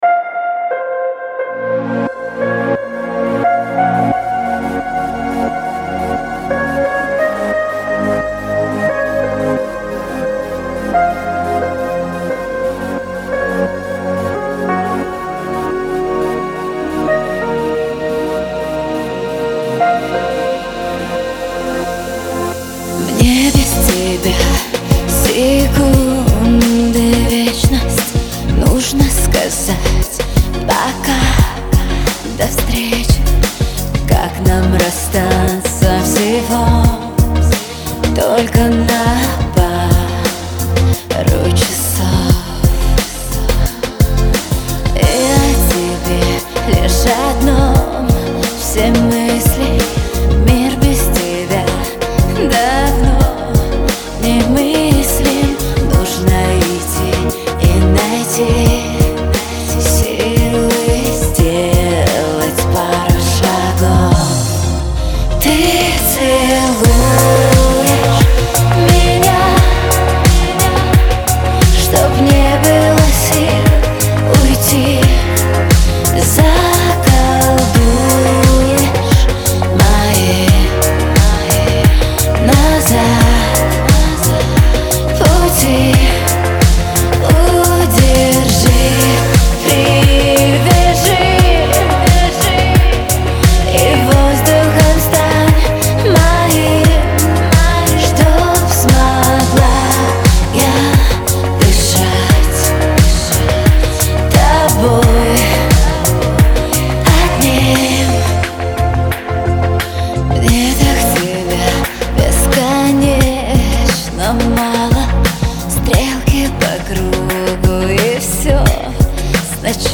в жанре поп, наполненный романтическим настроением